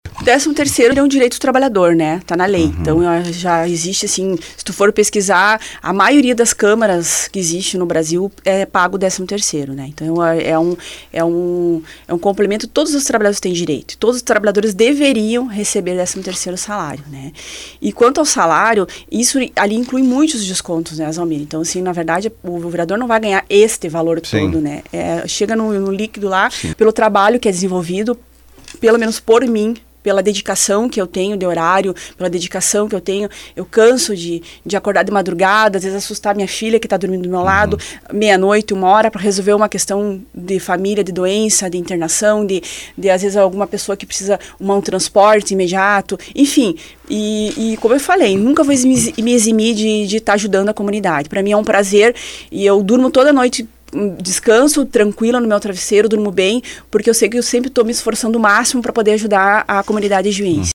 O texto retornou ao legislativo para os ajustes necessários, disse a proponente em entrevista ao Fatorama.